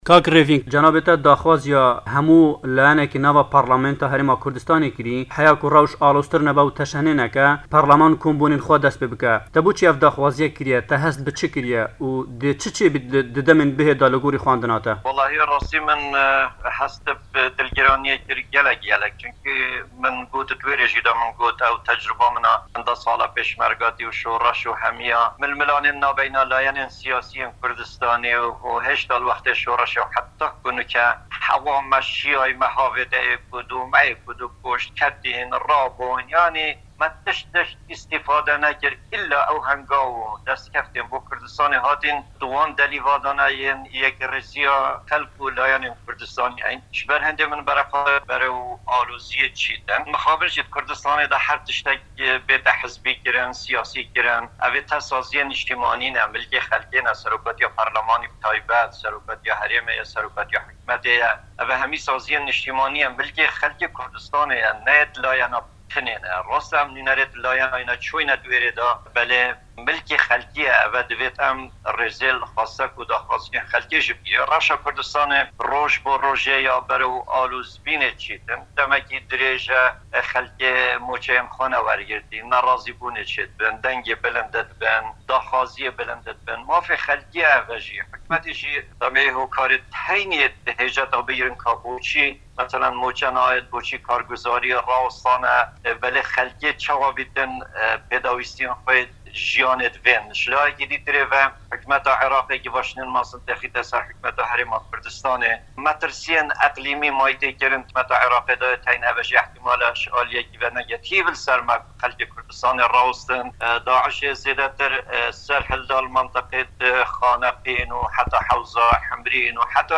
وتووێژ لەگەڵ ڕێڤینگ هروری